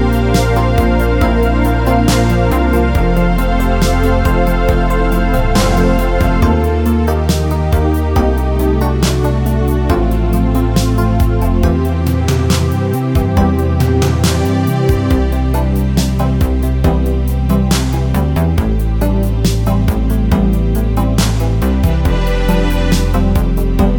no Backing Vocals Soundtracks 3:52 Buy £1.50